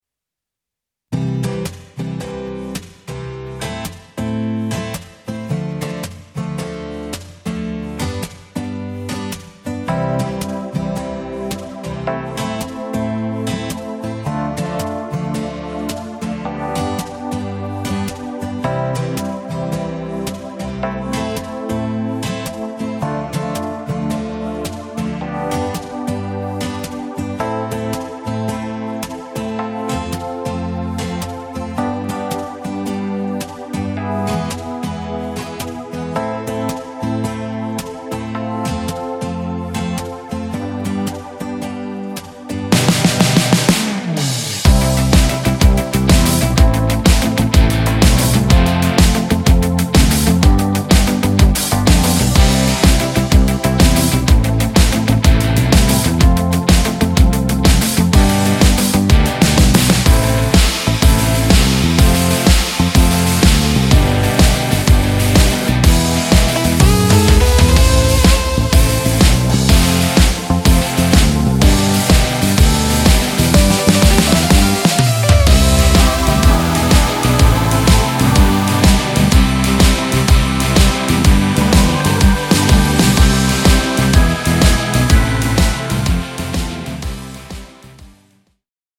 Wersja instrumentalna